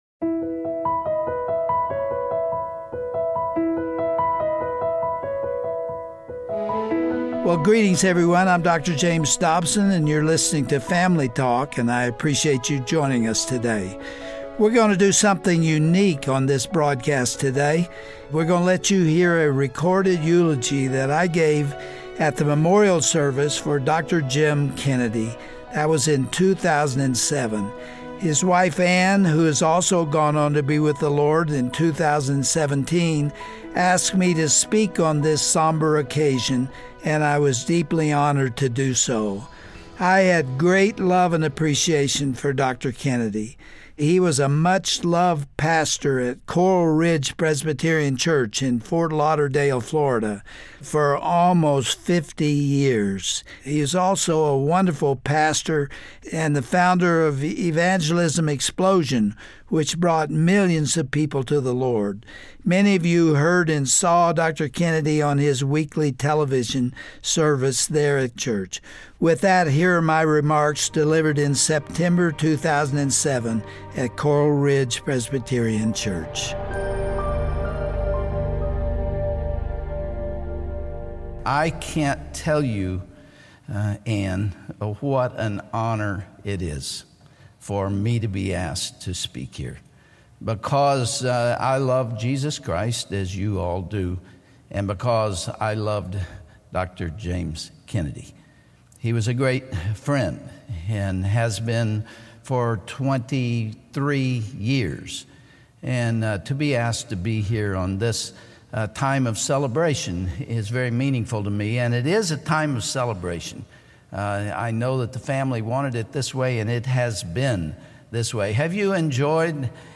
Todays Family Talk broadcast revisits Dr. Dobsons somber yet uplifting comments that he delivered at Dr. Kennedys memorial service. Dr. Dobson shared his admiration for, and friendship with this godly leader, before reflecting on Dr. Kennedys legacy. Dr. Dobson also challenged the next generation to boldly stand for righteousness like Dr. Kennedy did.